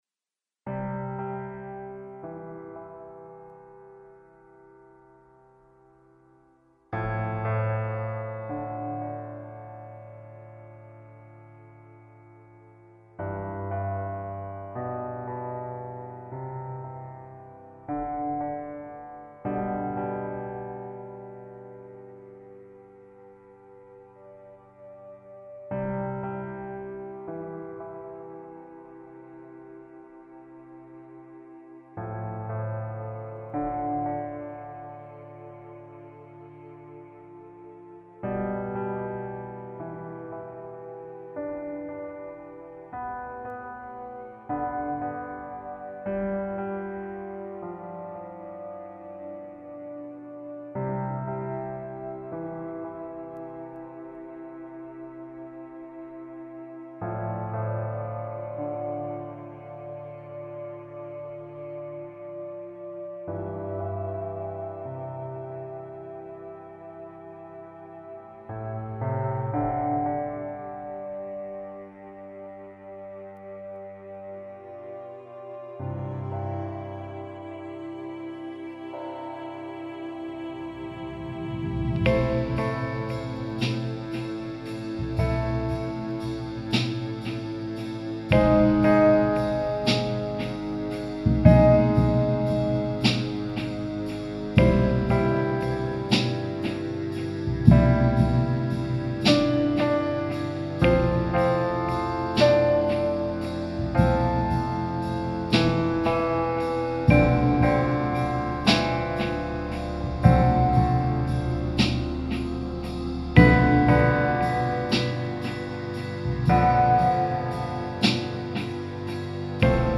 Here's an instrumental track of the Buddha chapter of Son of Strelka, Son of God.